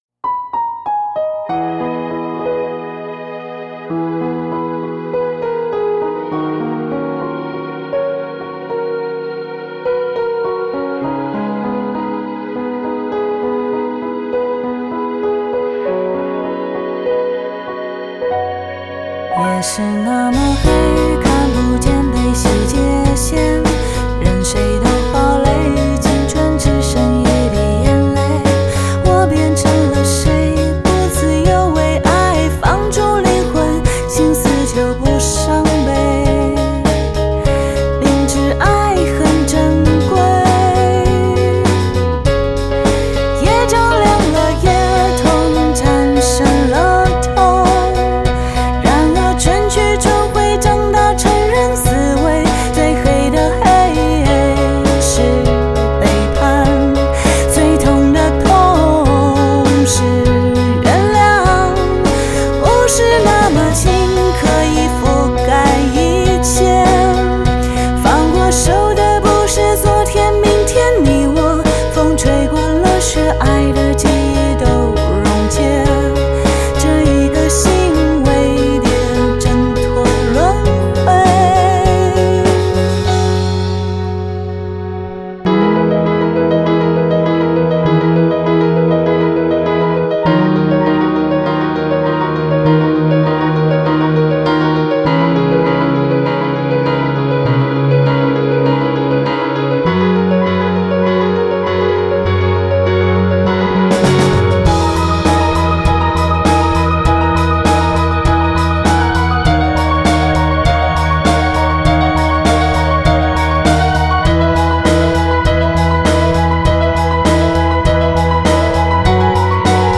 创新派民谣歌手。兼具知性与感性的气质，音色特殊而动人，温柔中略带悲凉，欢愉中隐含忧郁，情感浓郁丰盈。